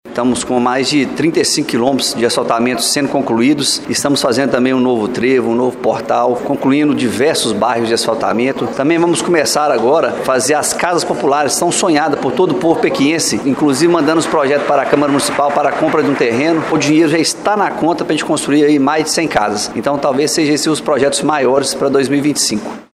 Em conversa com a nossa reportagem, Doutor André, falou sobre as expectativas para o segundo mandato e agradeceu mais uma vez a confiança do povo pequiense.